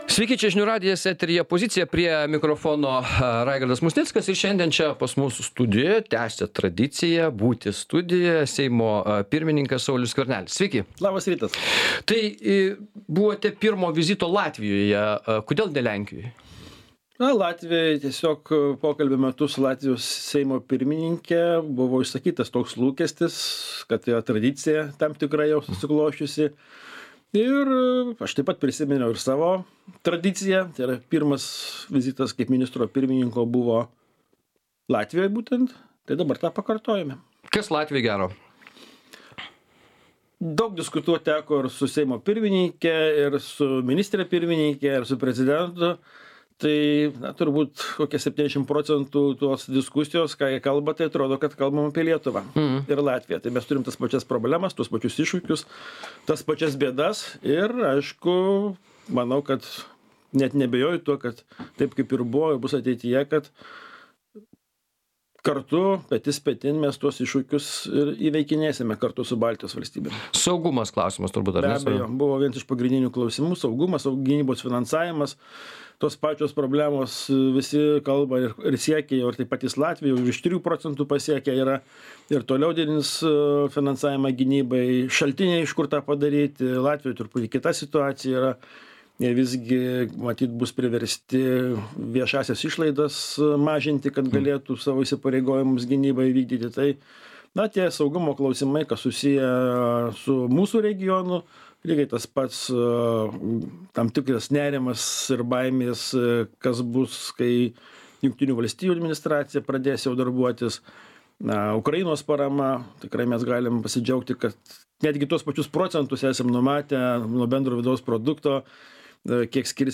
Laidoje dalyvauja Seimo pirmininkas Saulius Skvernelis.